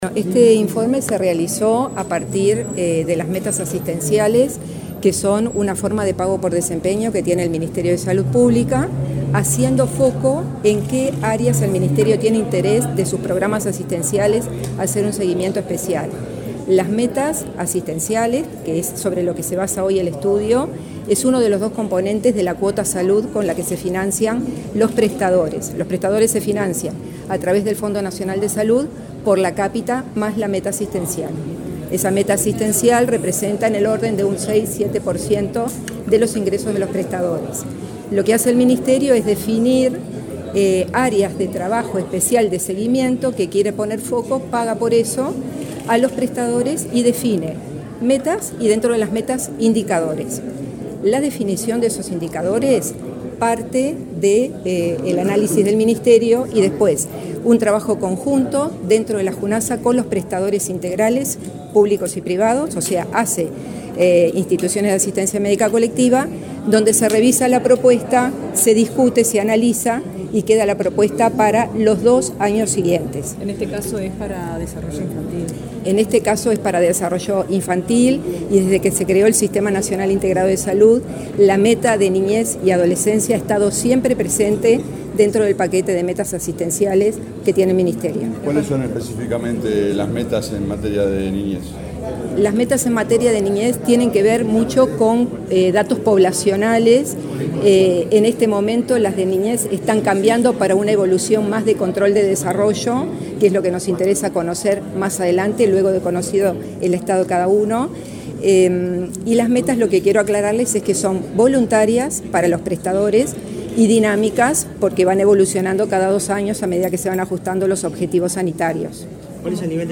Declaraciones de la titular de la Junasa, Alicia Rossi